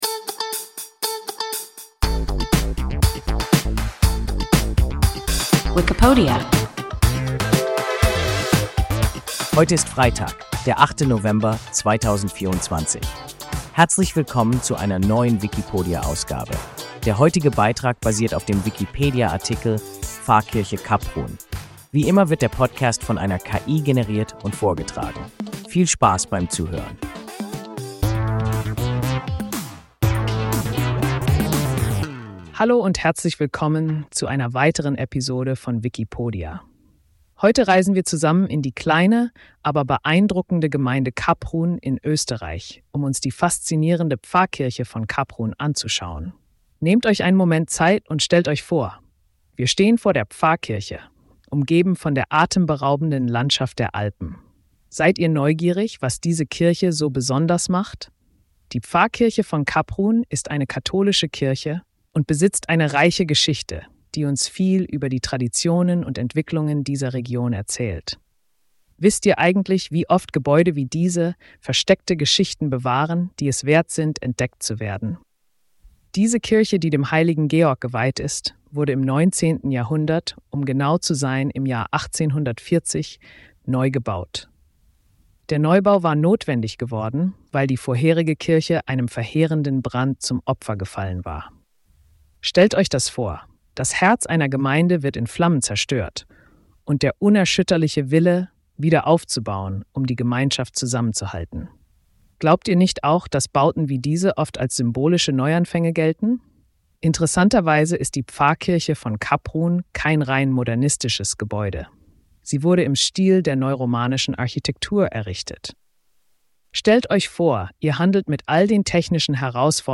Pfarrkirche Kaprun – WIKIPODIA – ein KI Podcast